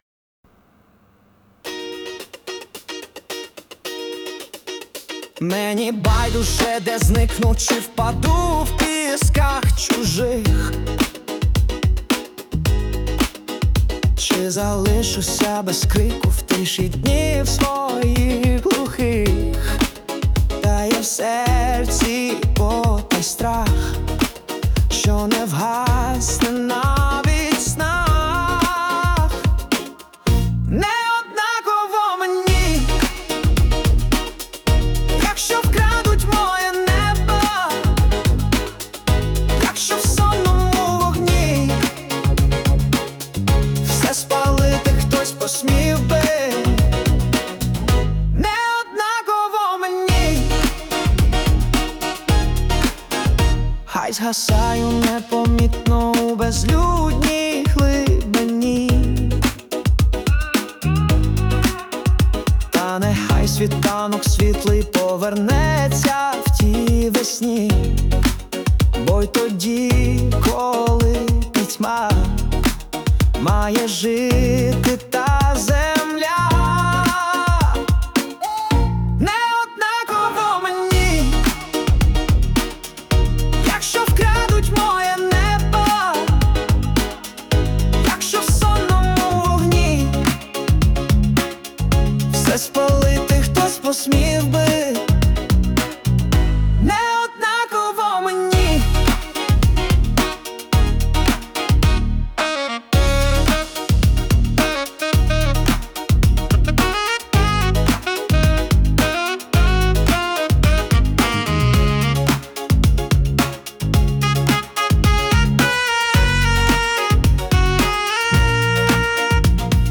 Стиль: Поп